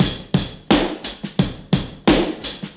Free drum groove - kick tuned to the A# note. Loudest frequency: 2048Hz
86-bpm-modern-breakbeat-sample-a-sharp-key-axZ.wav